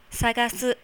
sagasu.wav